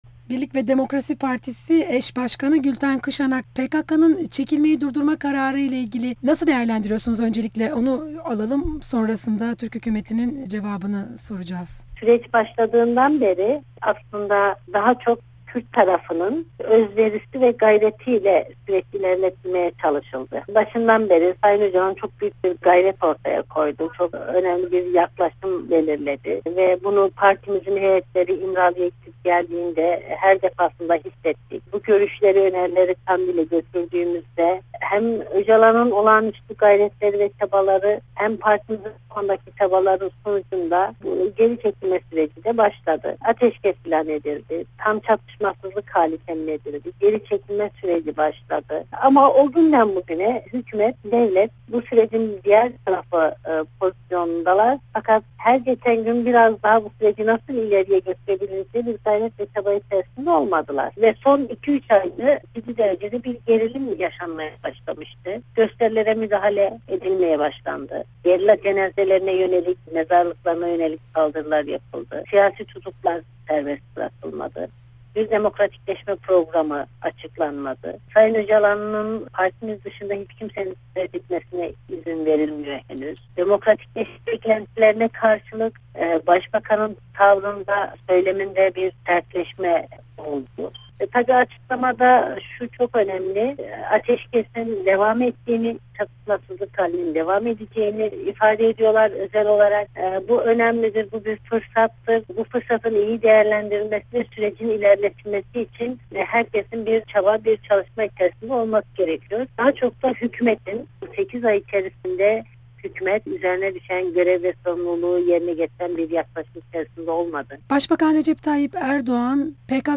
Gültan Kışanak ile Söyleşi